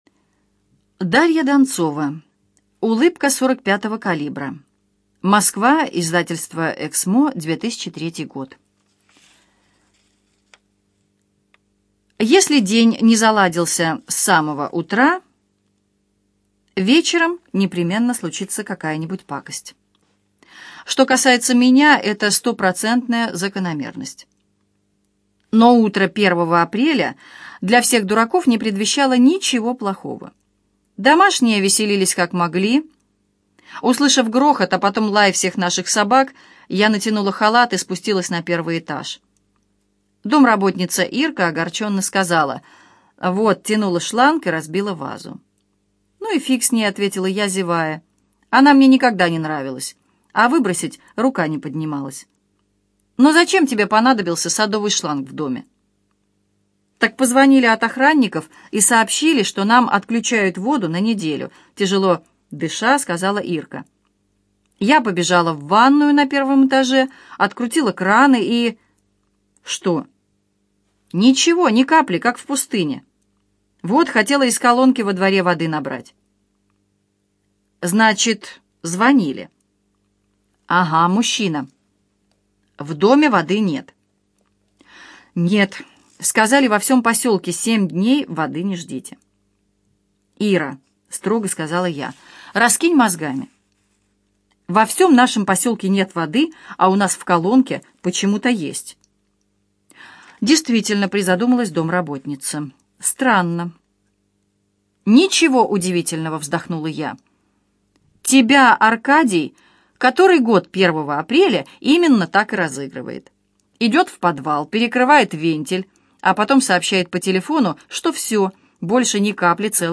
Аудиокнига Улыбка 45-го калибра - купить, скачать и слушать онлайн | КнигоПоиск